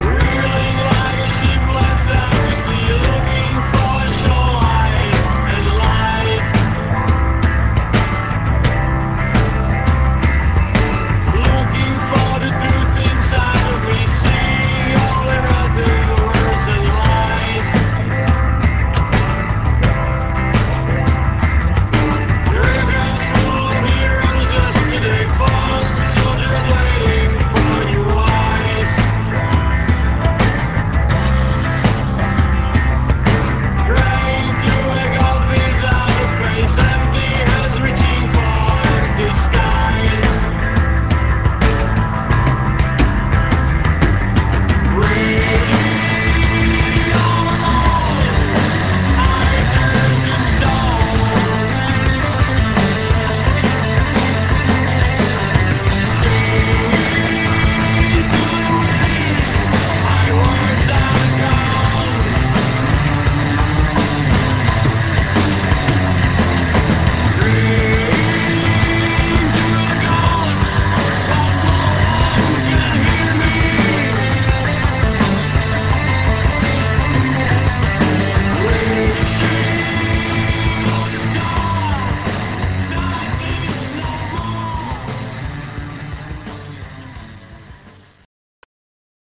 194 kB MONO